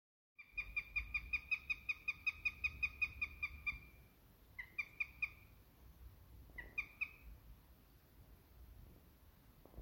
Da hörte ich laute, kräftige Vogelrufe.
Die klangen so kraftvoll, dass mir sofort klar war: Dieser Vogel muss größer als eine Krähe sein! Wieder schmetterte der Vogel seinen eindringlichen Ruf und ich folgte ihm.
Habichtruf.mp3